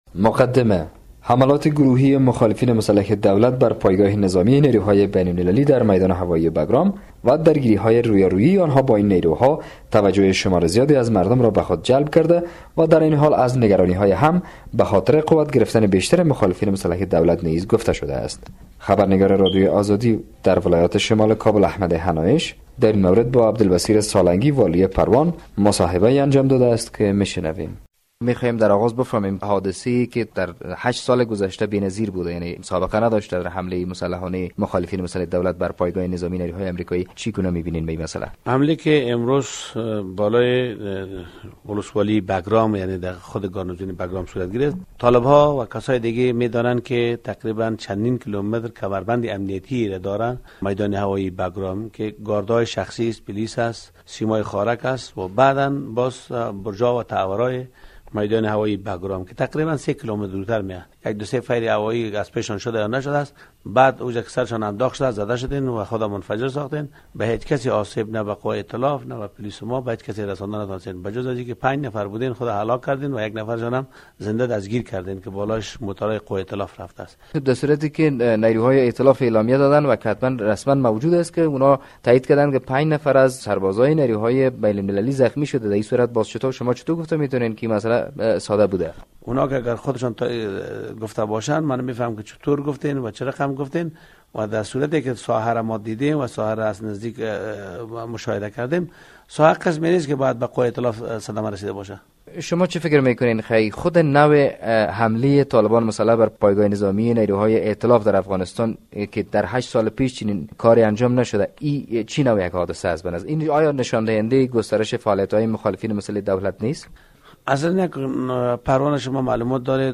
مصاحبه با با عبدالبصیر سالنگی در مورد حمله طالبان بالای میدان هوایی بگرام